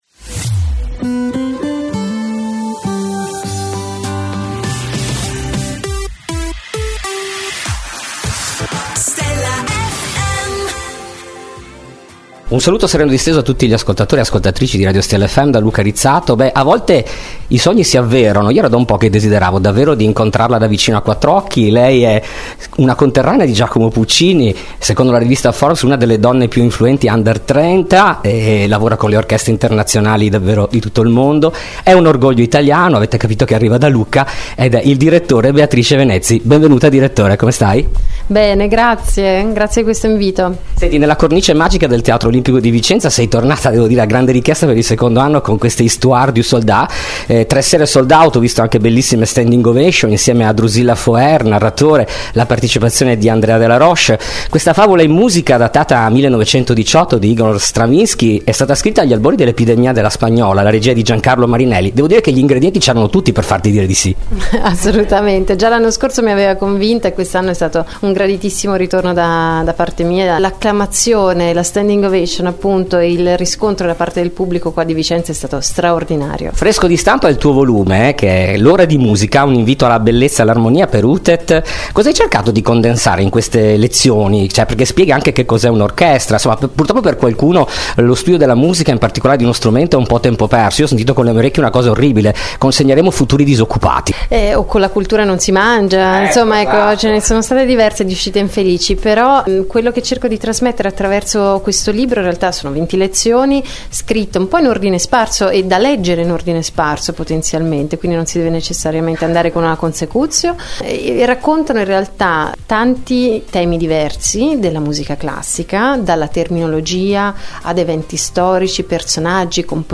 Intervista esclusiva dell’inviato per Stella FM a Beatrice Venezi.